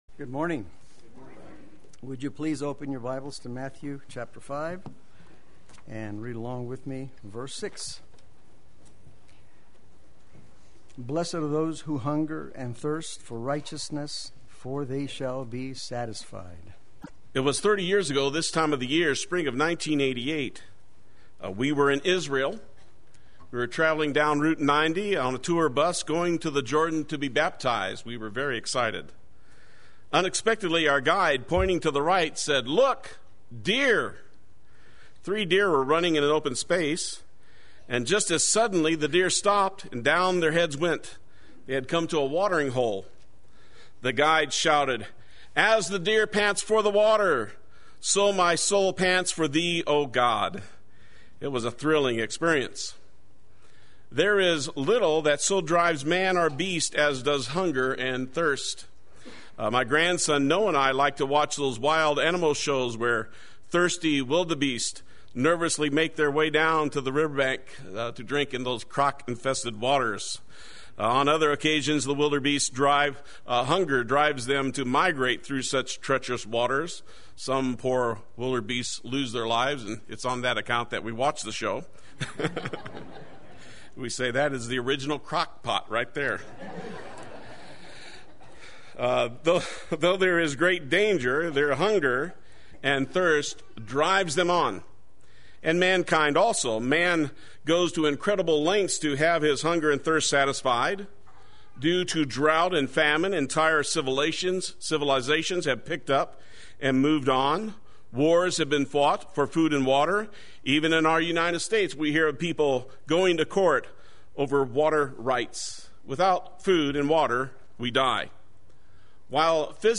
Play Sermon Get HCF Teaching Automatically.
God Satisfies the Hungry and Thirsty Sunday Worship